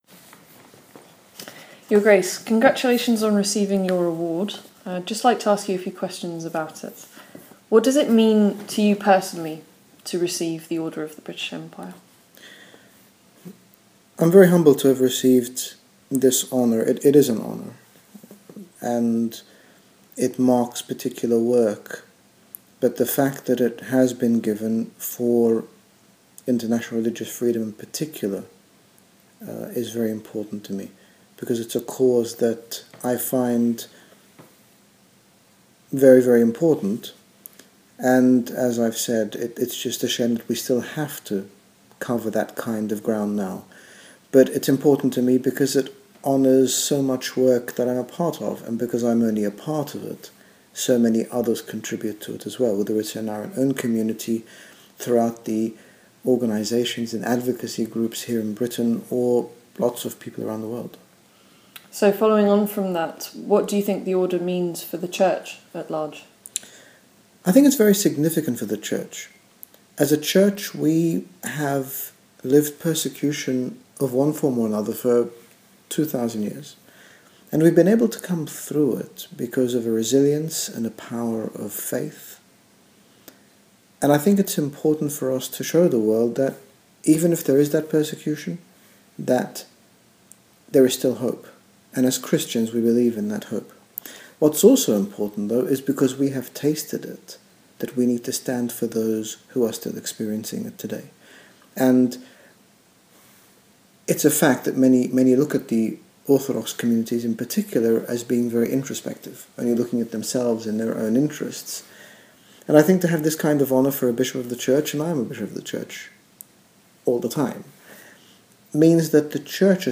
Interview With HG Bishop Angaelos re OBE.mp3